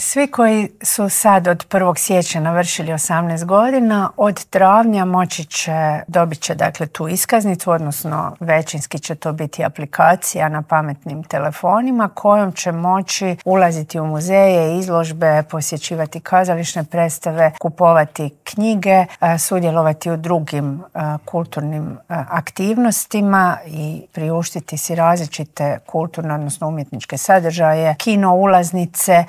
Treba 'ohladiti glave' i spustiti tenzije, zaključila je na kraju intervjua ministrica Obuljen Koržinek.